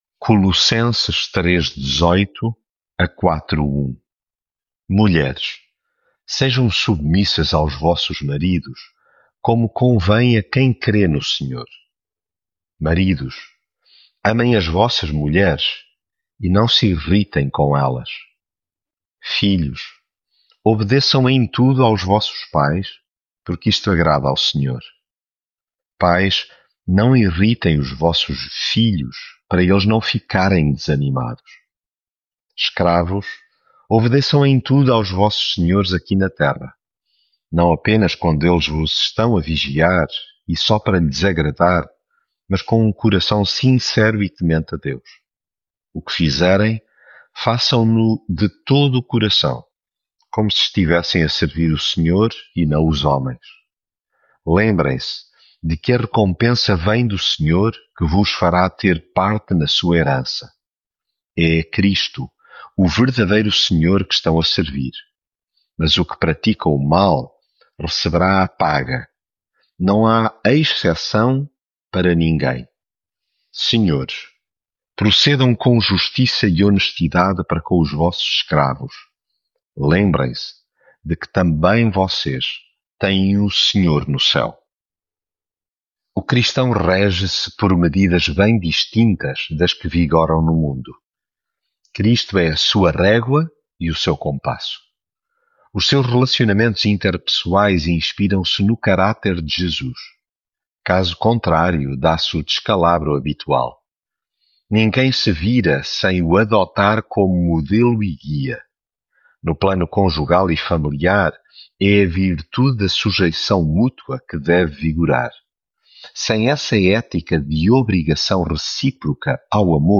Devocional
Leitura em Colossenses 3.18-4.1